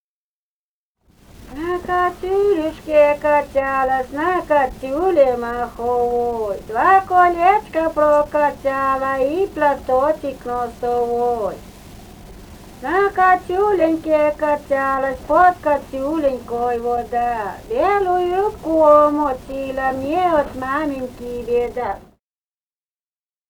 Живые голоса прошлого 035. «На качулюшке качалась» (качальные частушки).